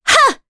Veronica-Vox_Attack2_jp.wav